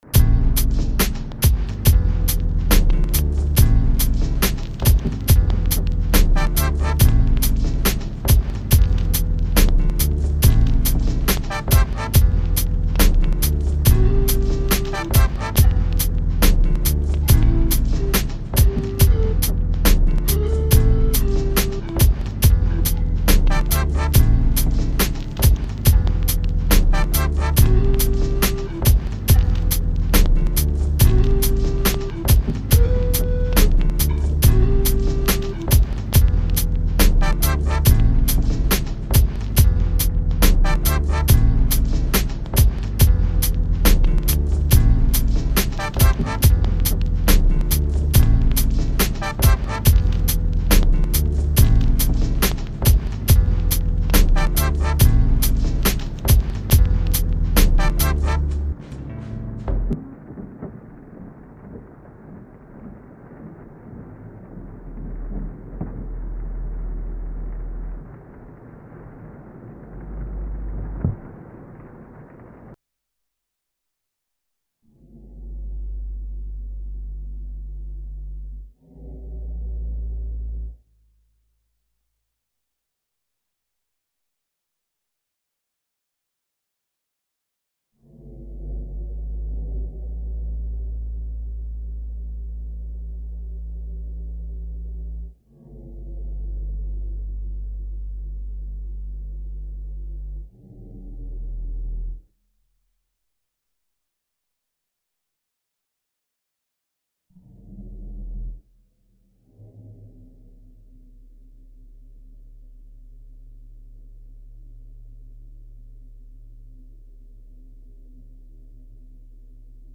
mp3,2240k] Рэп